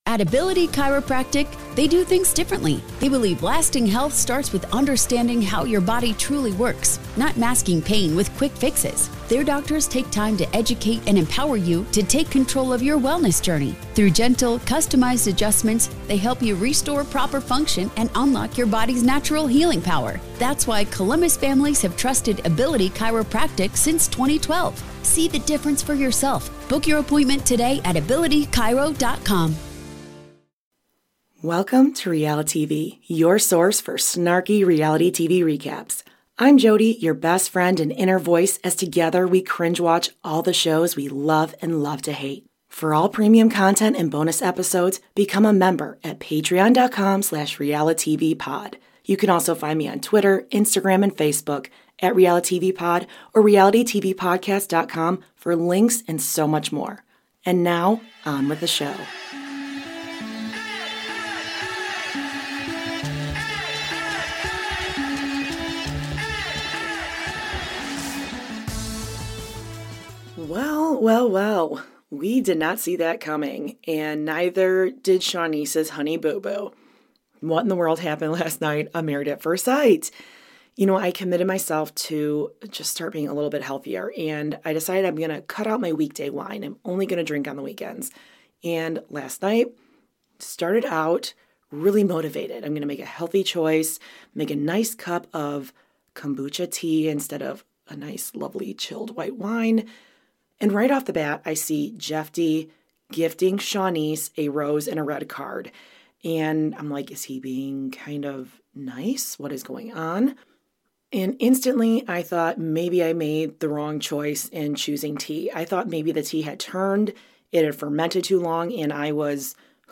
Apologies if the audio is as wonky